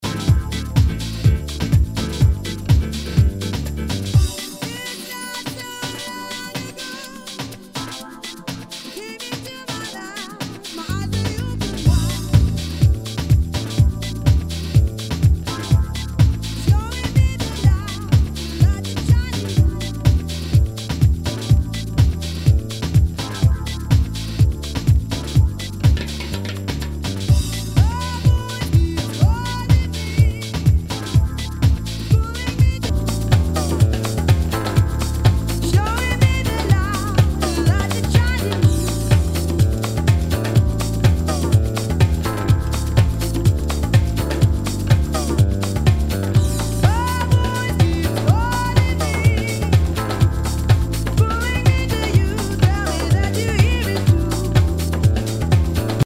HOUSE/TECHNO/ELECTRO
ナイス！ラテン・ヴォーカル・ハウス！
ジャケにスレキズ、抜けあり。全体にチリノイズが入ります